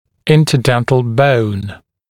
[ˌɪntə’dentl bəun][ˌинтэ’дэнтл боун]межзубная кость